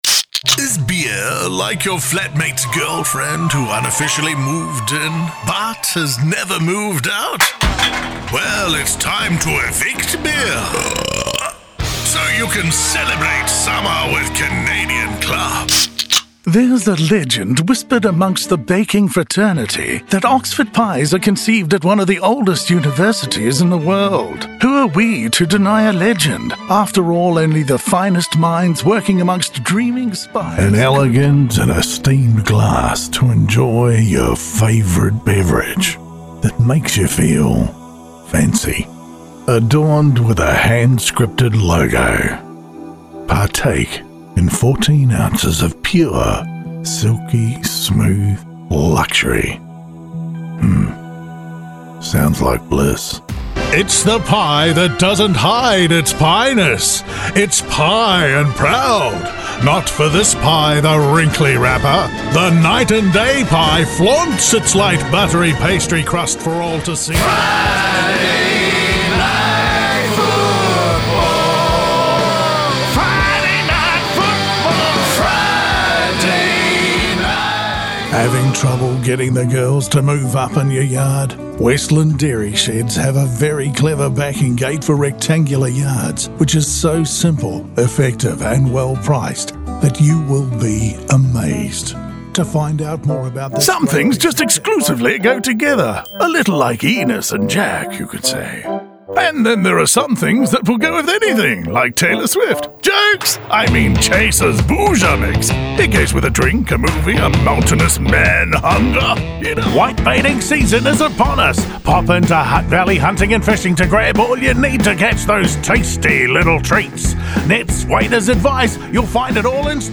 Demo
Adult, Mature Adult
COMMERCIAL 💸
conversational
gravitas
smooth/sophisticated
voice of god
warm/friendly